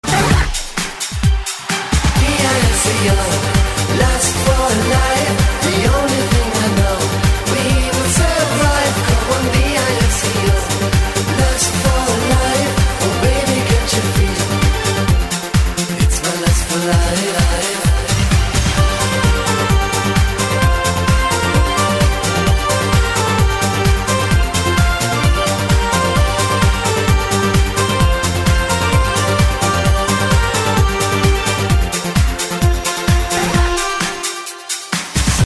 • Качество: 128, Stereo
диско